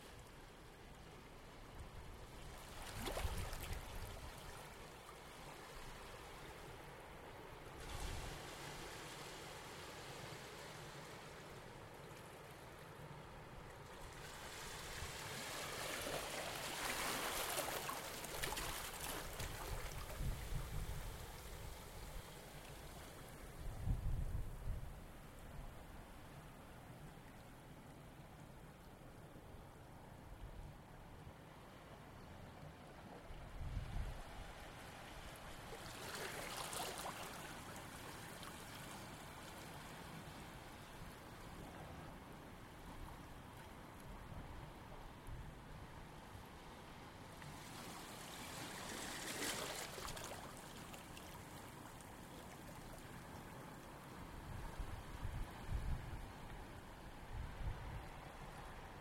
Waves lapping at a small cove between Warrnambool Breakwater and old aqaurium at night
Waveform Sound Archive; The water laps against the rocks in a small cove near Warrnambool Breakwater.